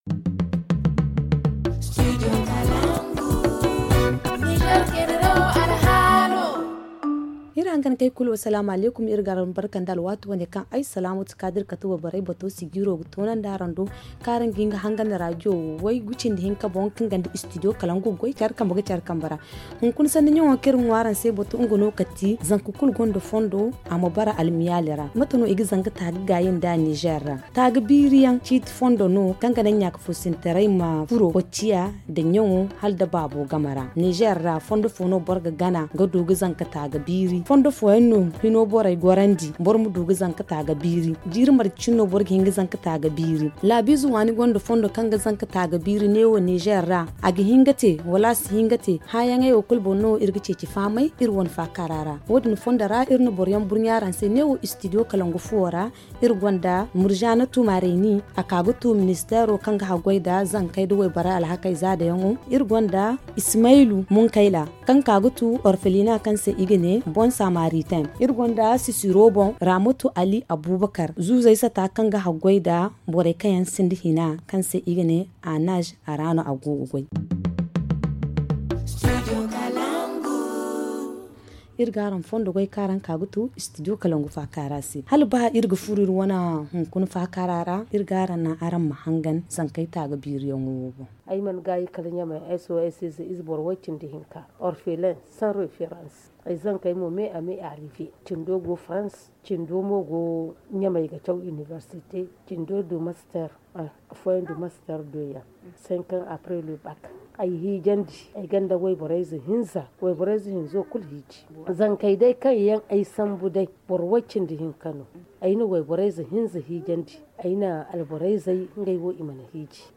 Le forum en zarma